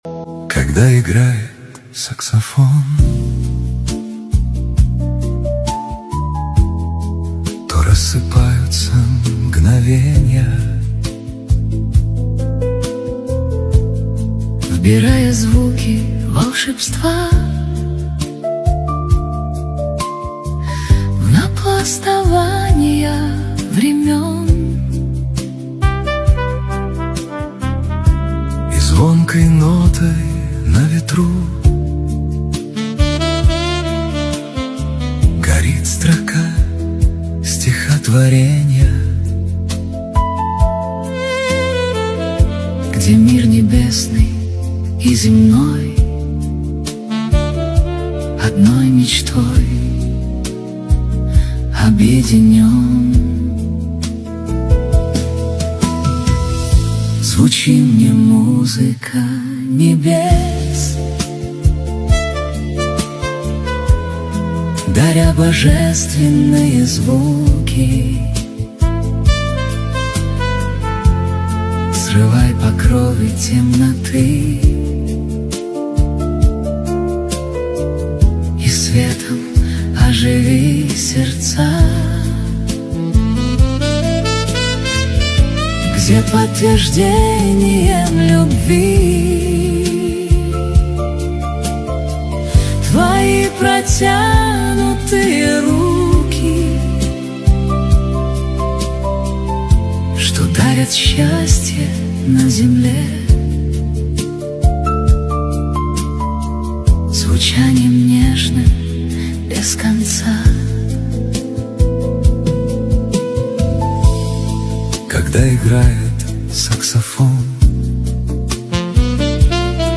Саксофон